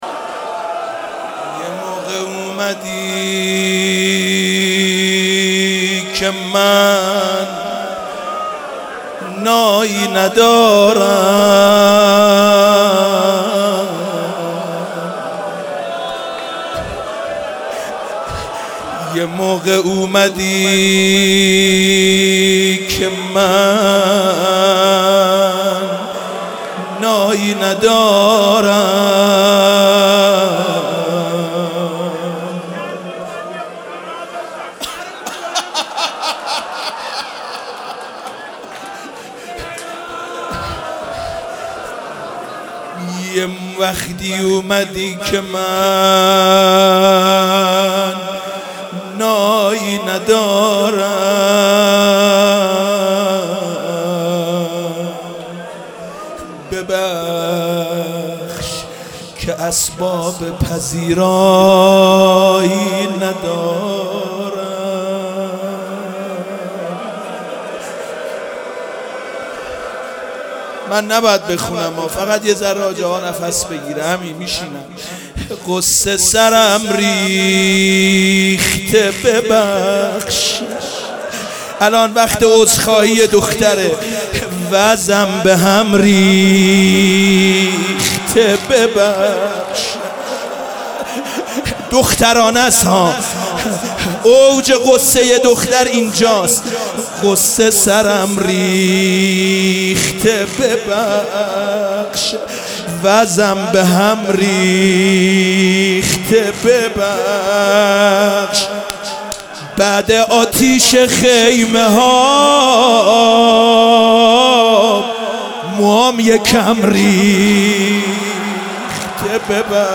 شب سوم محرم95/هیئت رایه العباس /چیذر